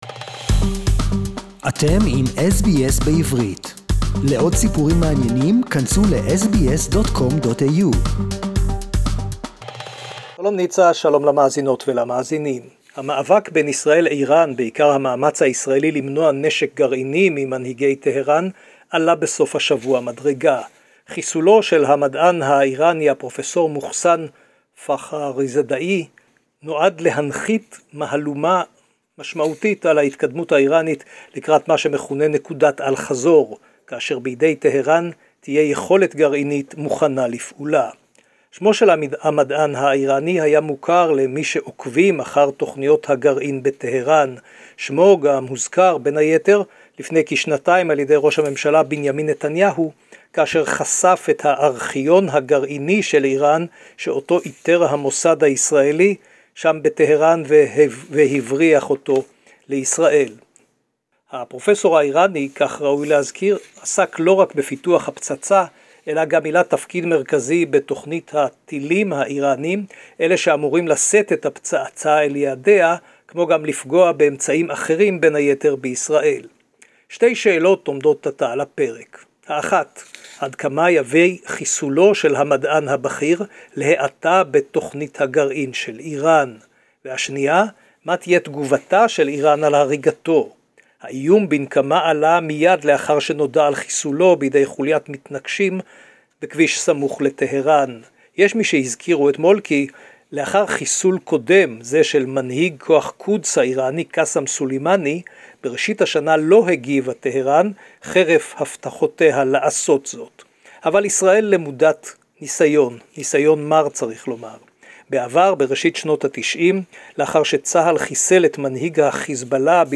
SBS Jerusalem report in Hebrew